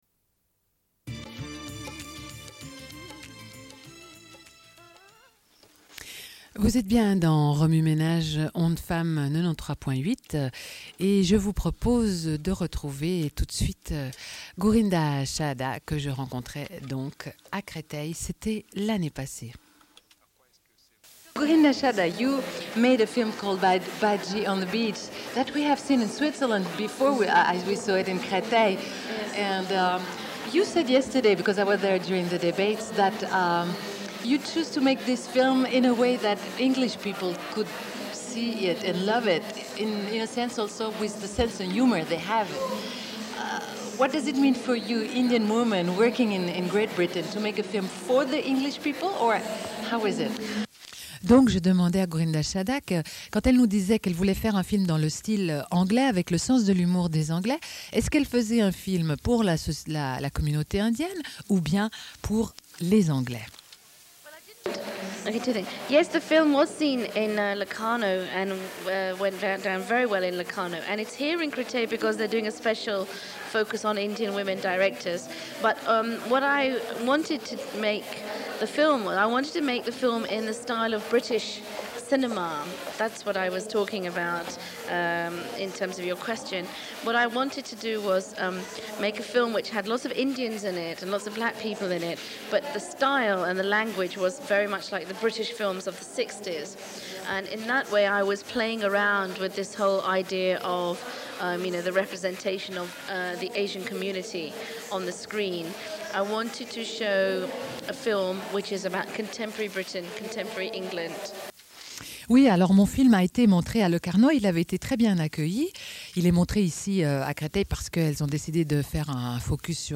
Suite de l'émission : diffusion d'un entretien avec Gurinder Chadha, réalisatrice britanico-indienne, réalisé à l'occasion du Festival de films de femmes de Créteil.